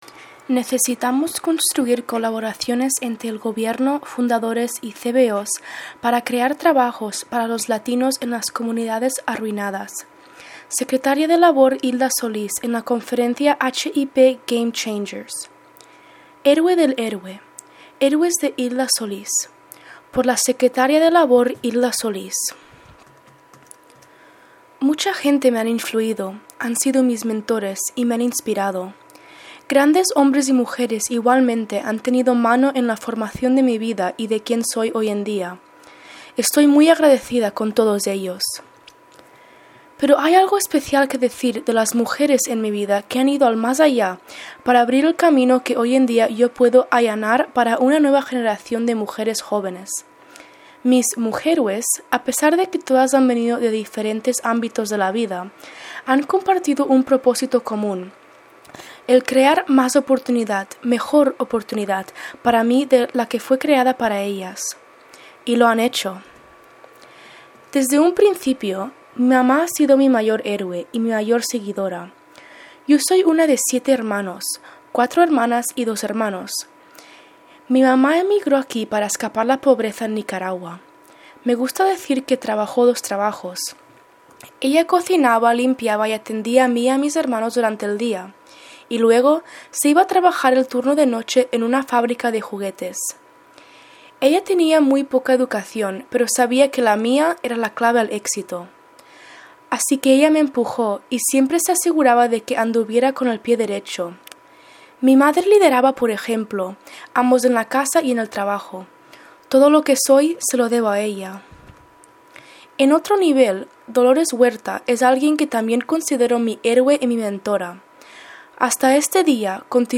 Narrado por